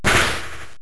normaldamage.wav